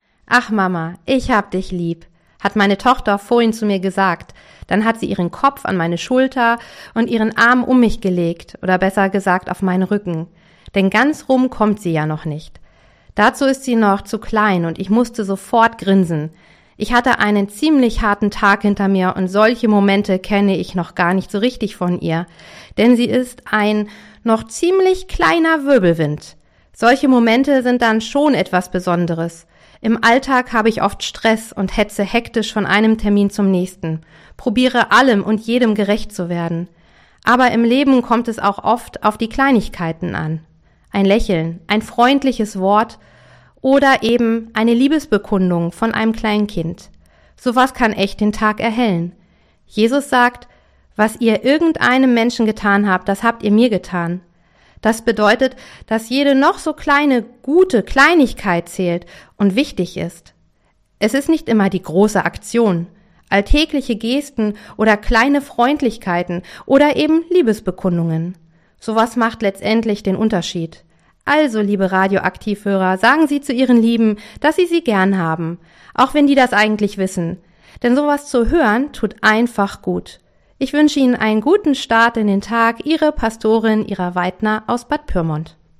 Radioandacht vom 14. Juli